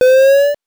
powerup_44.wav